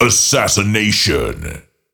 Фразы после убийства противника